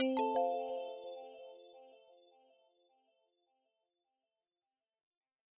We created luxurious compositions to evoke the ever-shifting skies, with a complete sound set of immersive original music and UX sounds heards throughout the traveler's journey.
Alert
delta-alert.wav